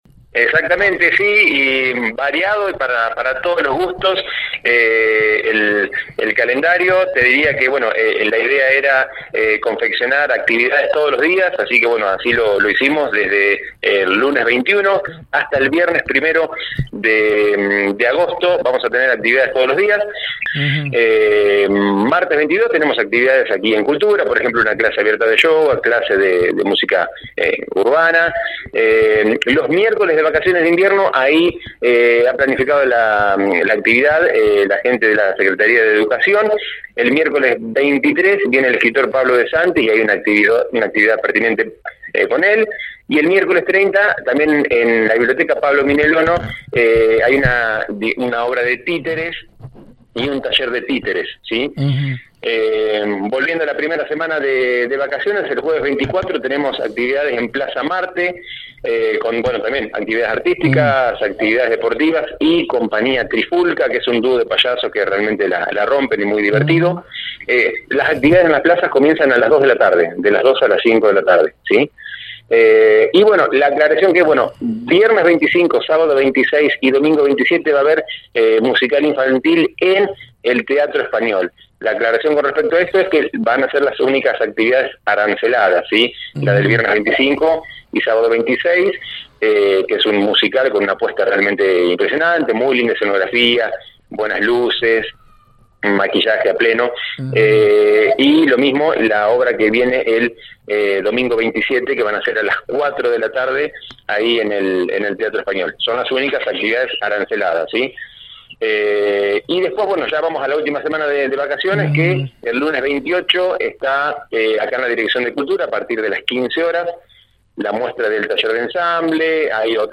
Ya está en marcha el receso invernal y en nuestra ciudad son múltiples las opciones para nuestro chicos. En la mañana de la 91.5 hablamos con el actual titular del área de Cultura municipal quien brindó todos los detalles.